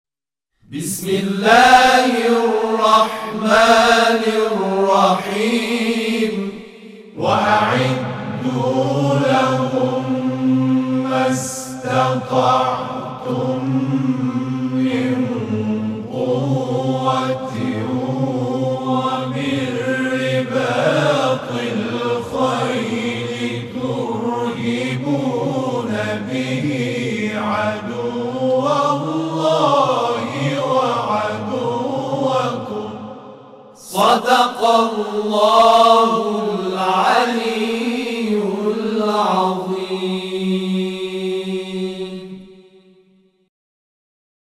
گروه مدیحه‌سرایی و همخوانی محمدرسول الله(ص) اقدام به تولید آثار صوتی و تصویری با محوریت جمع‌خوانی آیات قرآن با موضوع مبارزه و مقاومت کرده که نخستین اثر تولیدی همخوانی آیه ۶۰ سوره مبارکه انفال است.
صوت جمع‌خوانی آیه 60 سوره انفال